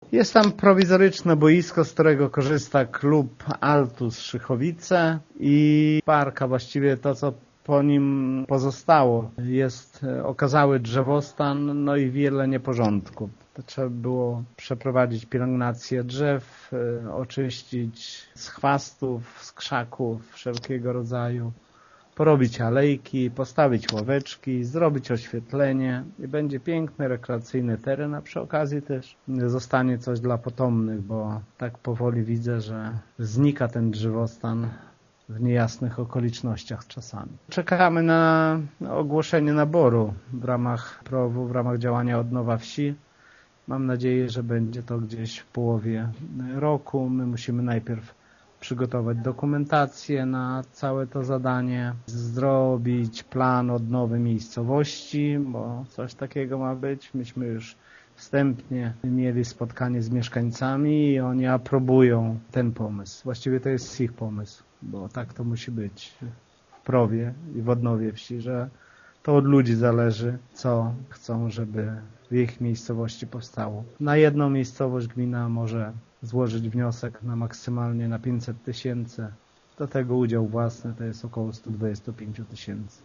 „Na marcowej sesji Rada Gminy przyjęła jednak uchwałę o nieodpłatnym nabyciu prawie 3 hektarów ziemi, by w przyszłości zagospodarować teren na cele rekreacyjno-wypoczynkowe. Pieniądze na ten cel samorząd zamierza pozyskać z funduszy unijnych” – mówi wójt Lech Szopiński: